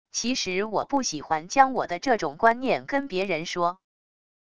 其实我不喜欢将我的这种观念跟别人说wav音频生成系统WAV Audio Player